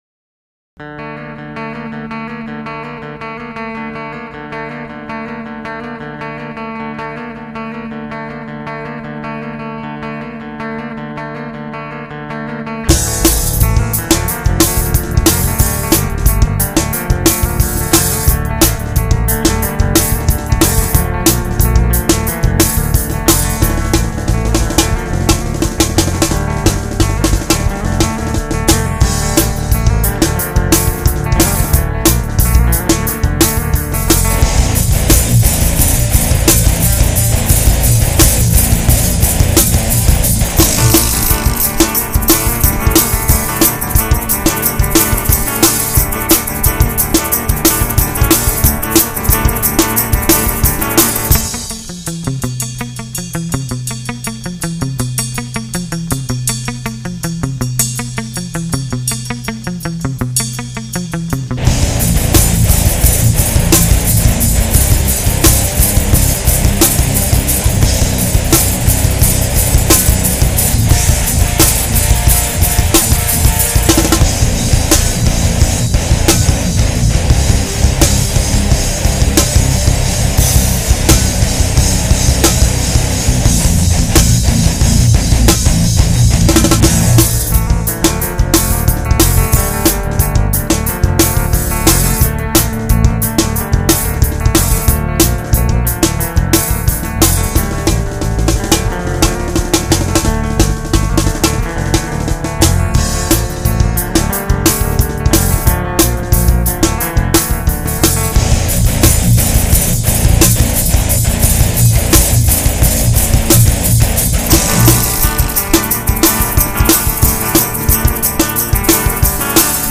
(no vox for this one yet)